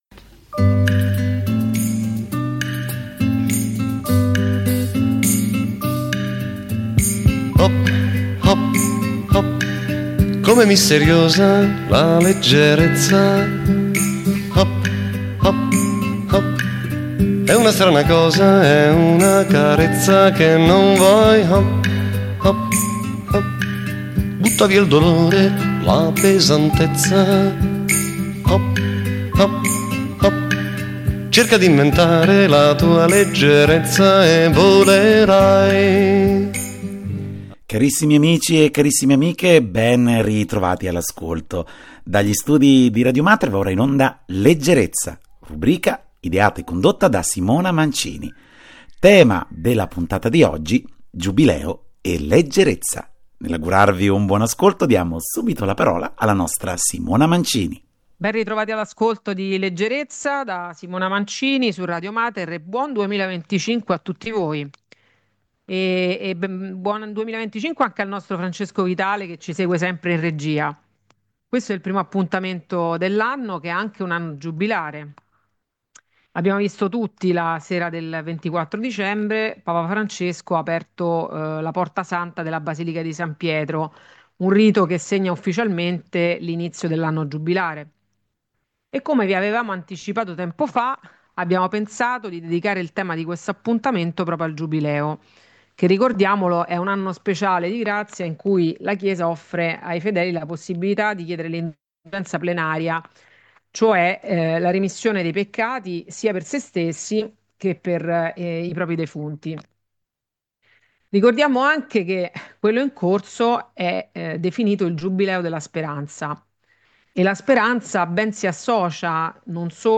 Sulle note di musiche suggestive